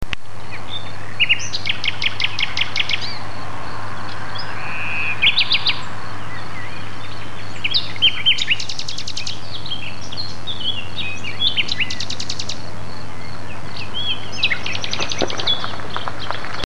Teppes de Verbois, 27.04.07
et la beauté du chant du rossignol:
rossignol2.mp3